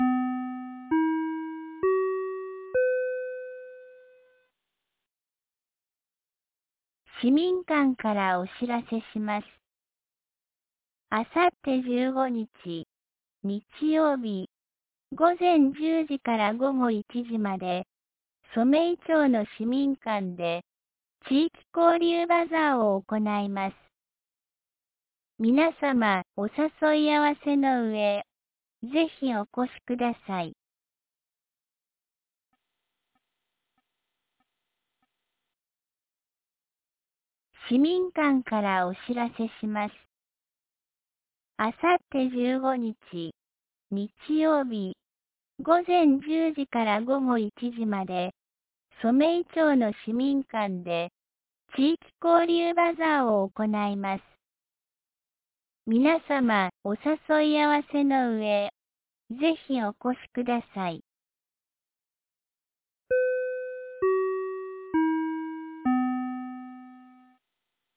2024年12月13日 17時13分に、安芸市より全地区へ放送がありました。